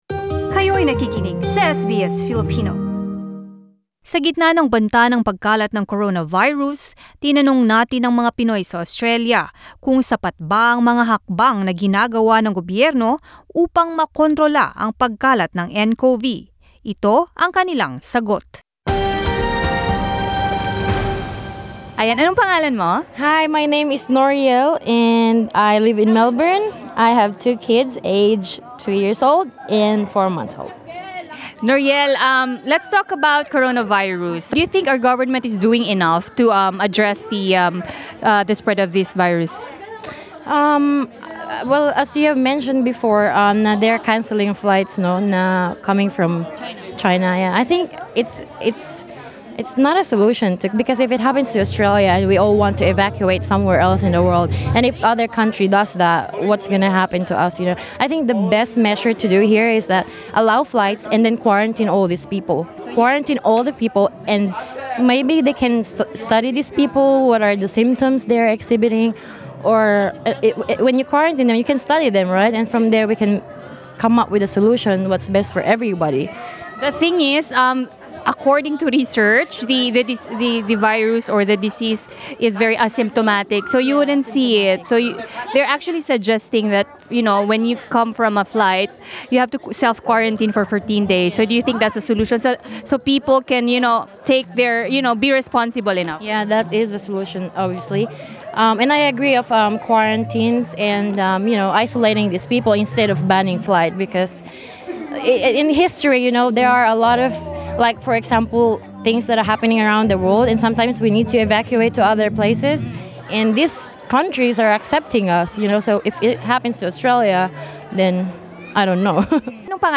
Vox Pop: Is the government taking enough measures to control Ncov spread?
Is the Australian government taking enough measures to control the spread of the deadly novel coronavirus? We took to the streets to find out.
Filipinos in Australia share their opinion about the novel coronavirus Source: Supplied